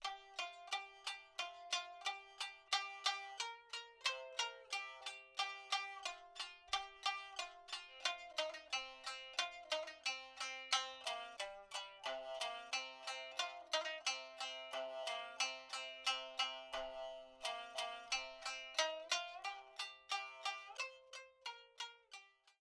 Traditional fishing folk song (Minyo) for shamisen.
• honchōshi tuning (C-F-C)
• Tuning: Honchōshi